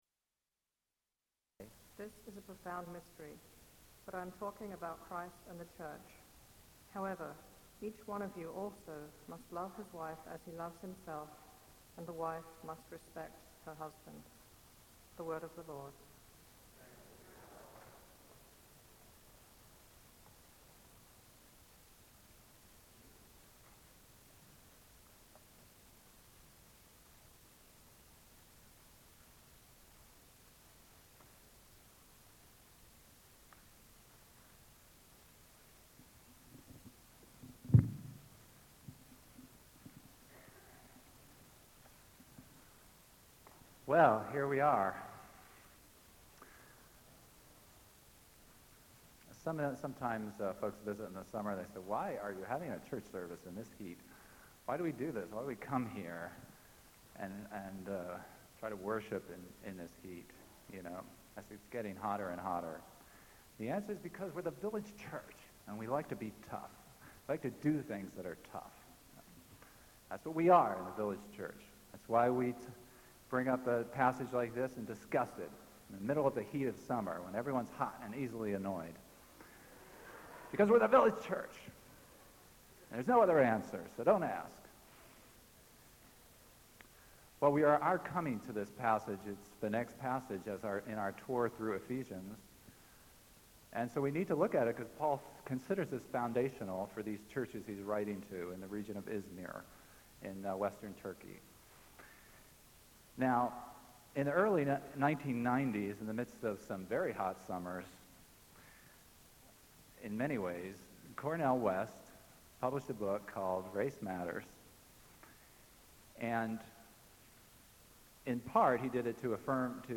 Today’s post highlights the first sermon I ever preached on gender.